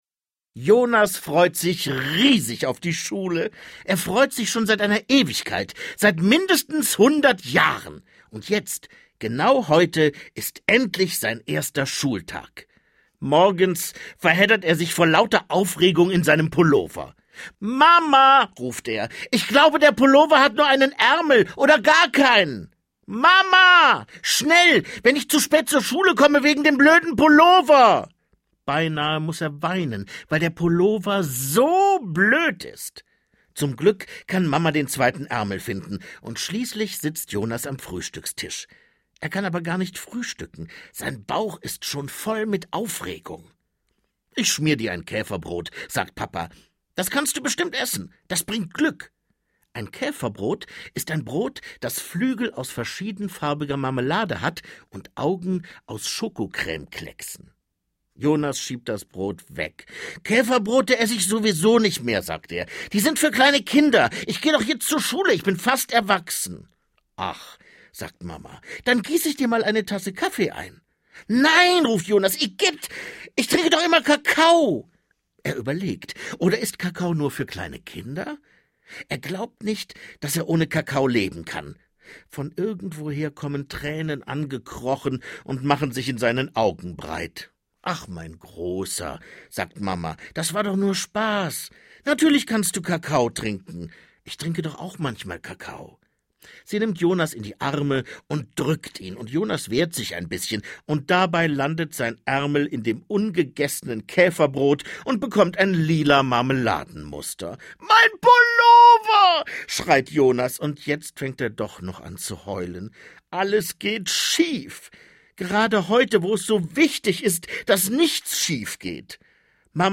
Dirk Bach (Sprecher)
Lustige Geschichten über den großen Tag - gelesen von Dirk Bach!
Reihe/Serie DAV Lesung für Kinder